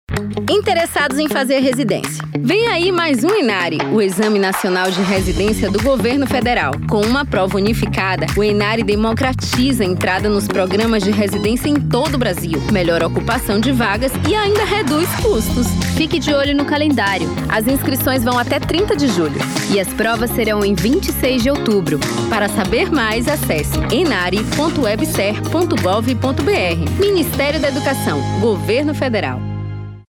Spots Spot MDA - Lançamento Plano Safra Agricultura Familiar em Alagoas Prazo de veiculação: até 04/09/24.